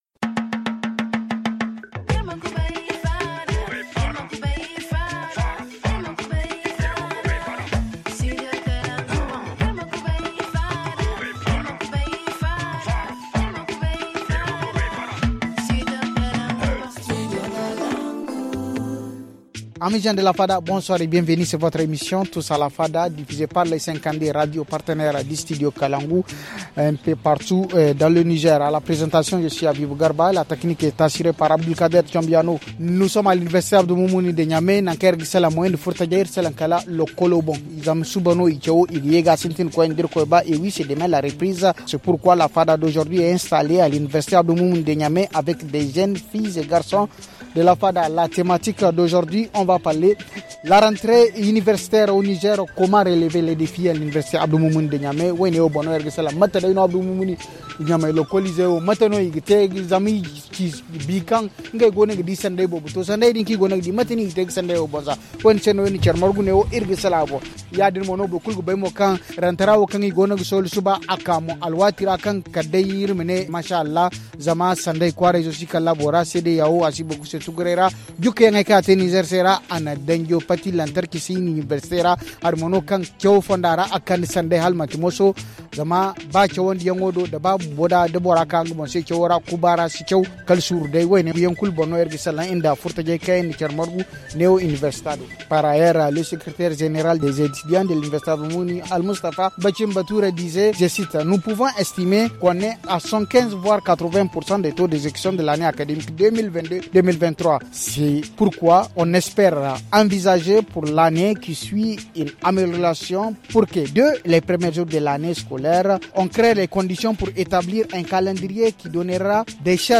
C’est pourquoi la fada d’aujourd’hui est installée à l’université Abdou Moumouni de Niamey avec les jeunes étudiants filles et garçons.